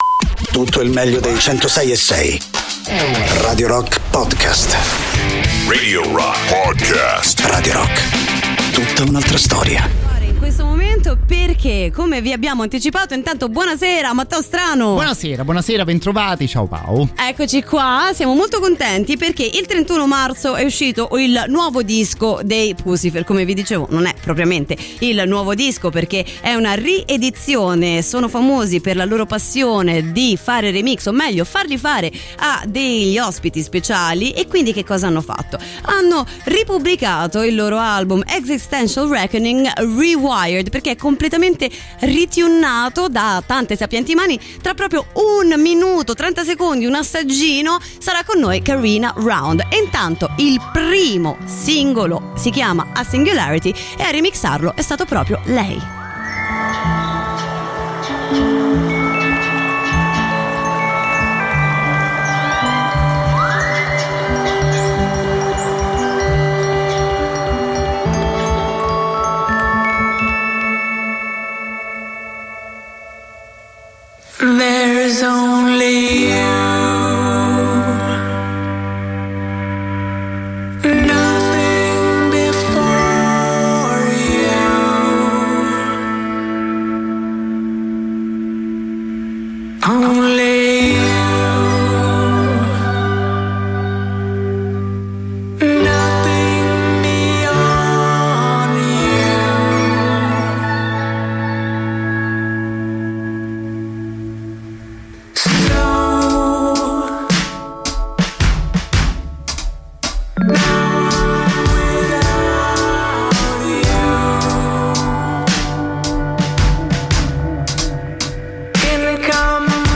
Interviste: Carina Round (Puscifer) (06-04-23)